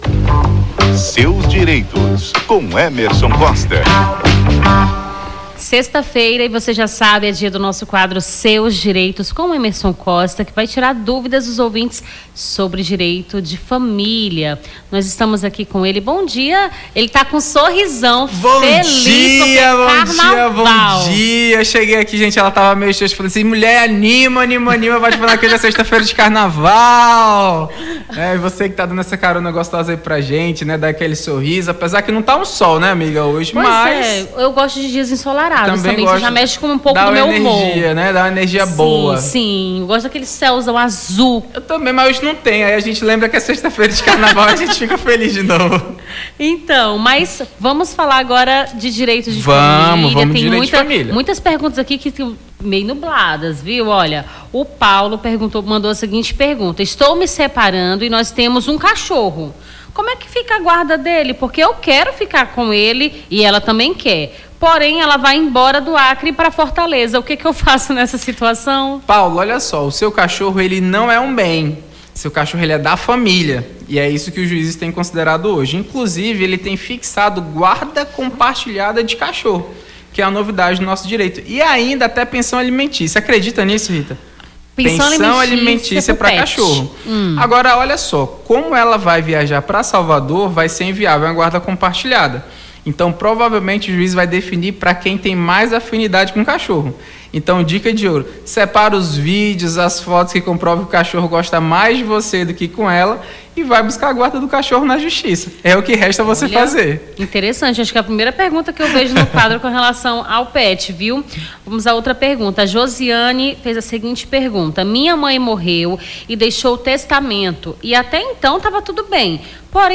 Seus Direitos: advogado esclarece dúvidas dos ouvintes sobre direito de família